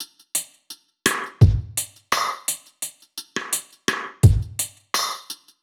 Index of /musicradar/dub-drums-samples/85bpm
Db_DrumKitC_Dry_85-02.wav